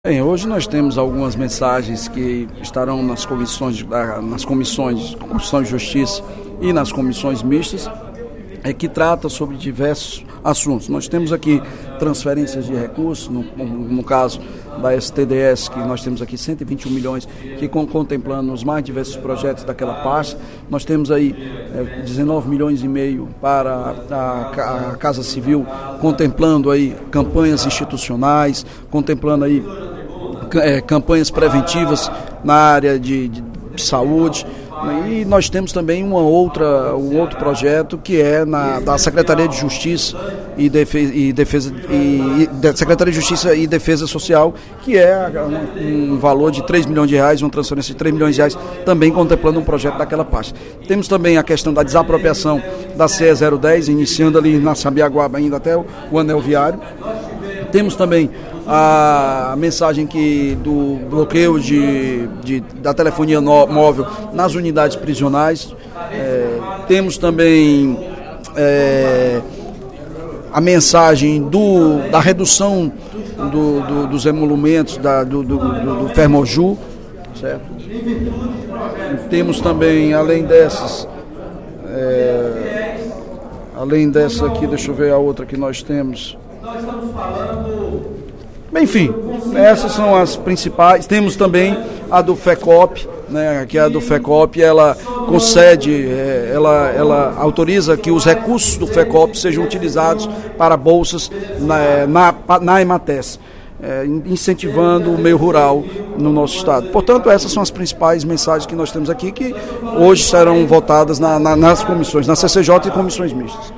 O líder do Governo na Assembleia Legislativa, deputado Evandro Leitão (PDT), destacou, durante o primeiro expediente da sessão plenária desta quarta-feira (24/02), mensagens do Poder Executivo que  deverão ser votadas pela Comissão de Constituição, Justiça e Redação da AL. O parlamentar pediu apoio aos colegas deputados qu as matérias possam ser apreciadas em plenário amanhã (25/02).